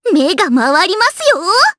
Rehartna-Vox_Skill1_jp.wav